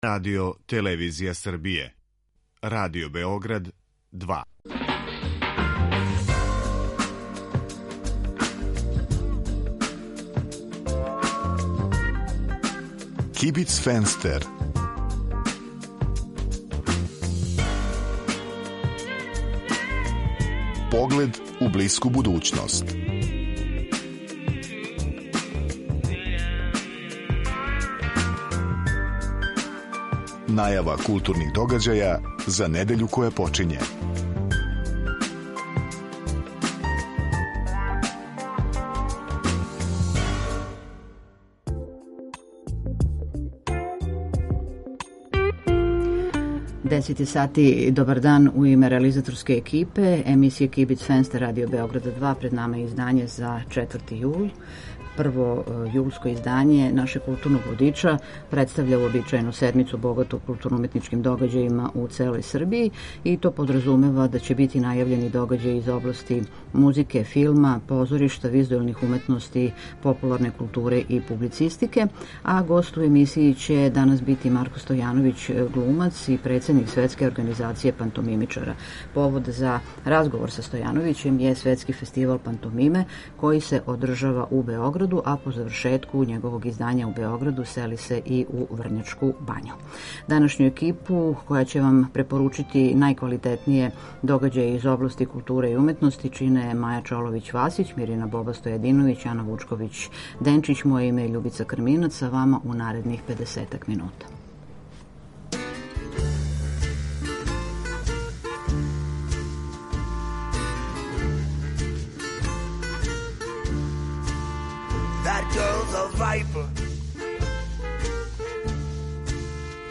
Недељни културно-уметнички водич